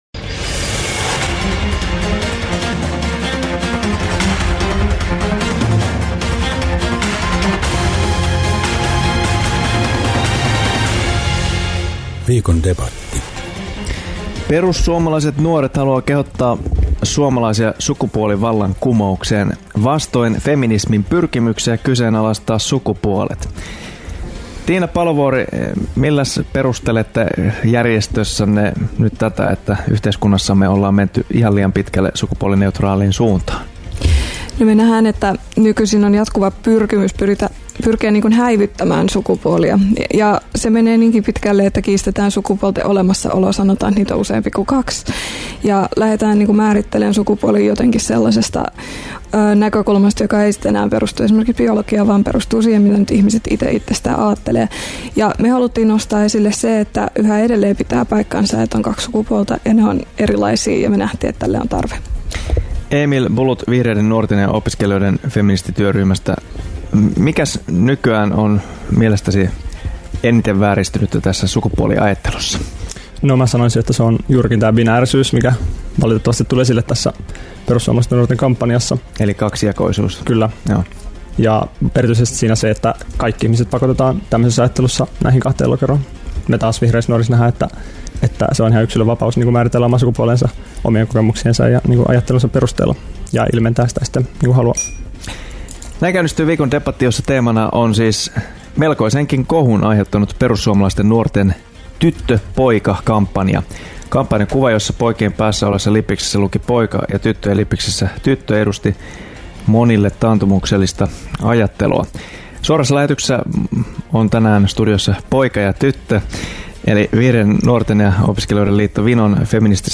Sukupuolien roolittelu kuumensi tunteet Radio Dein studiossa
Viikon debateissa tunteet kuumenevat aika ajoin. Ohjelman ideana on esitellä viikottain joku mielenkiintoinen teema, josta kaksi eri suunnista lähestyvää vierasta debatoivat.
Viikon-debatti-osa-1_sukupuolien-jako.mp3